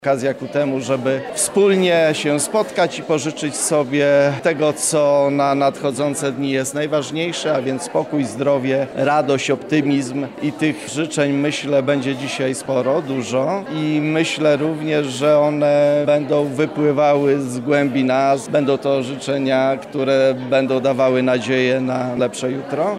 Wczoraj (14.12) w Akademickim Centrum Kultury i Mediów odbyła się Wigilia Akademicka.
-mówi prof. Radosław Dobrowolski, rektor UMCS.